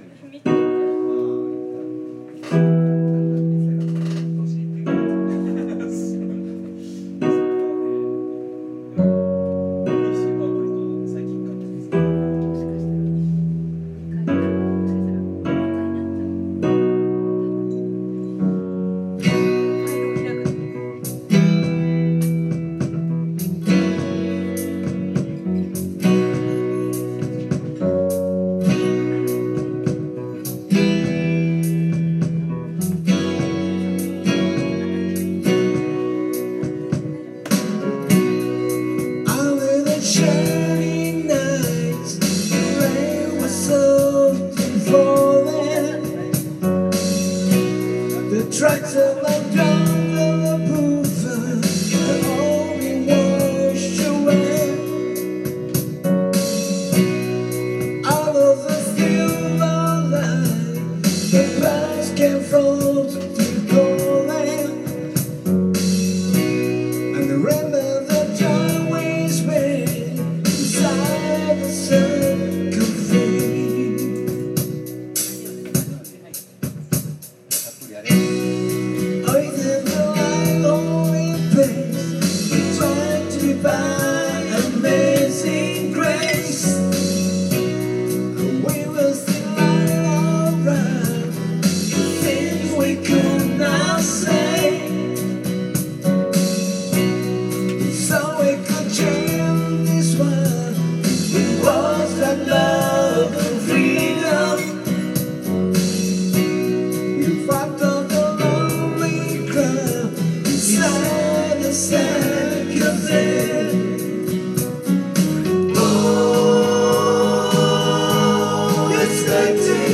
Duet & Chorus Night Vol. 13 TURN TABLE